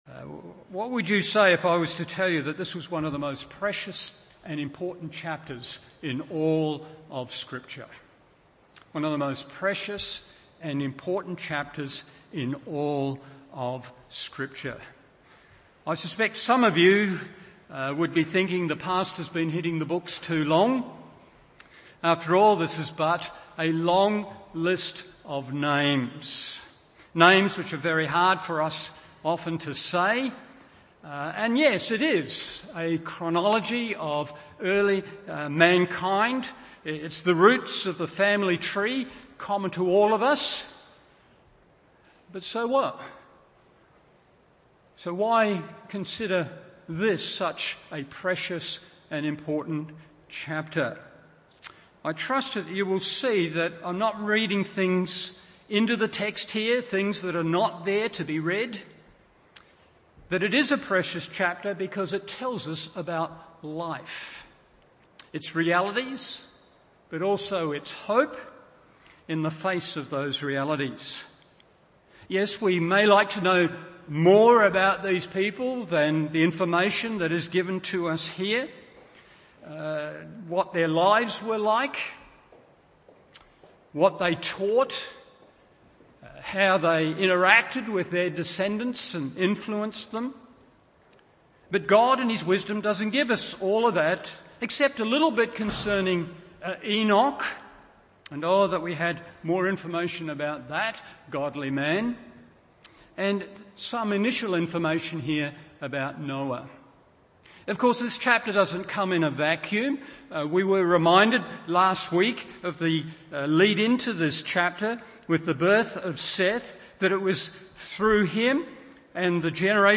Morning Service Genesis 5:1-31 1.